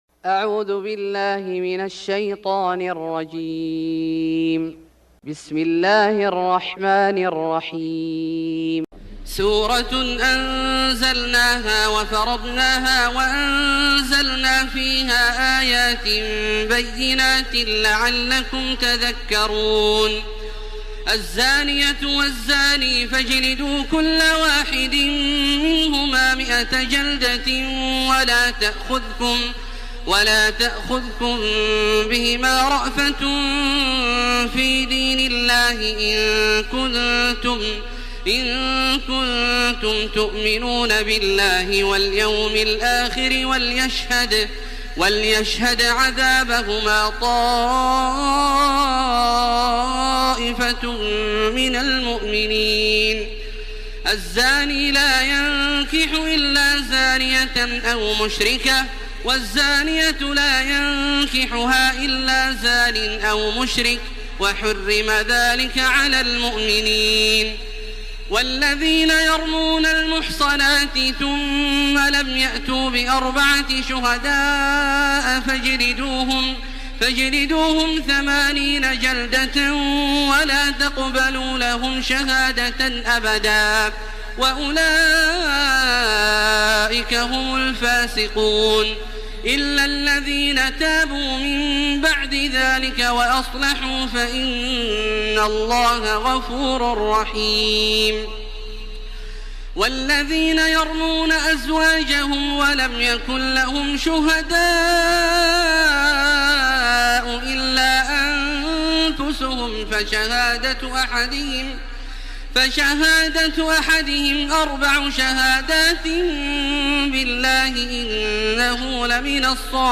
سورة النور Surat An-Nur > مصحف الشيخ عبدالله الجهني من الحرم المكي > المصحف - تلاوات الحرمين